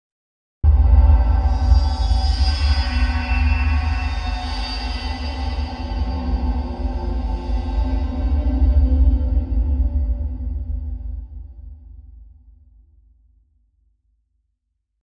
Звуки кошмара
6. Фильм ужасов триллер